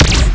fire_particle2.wav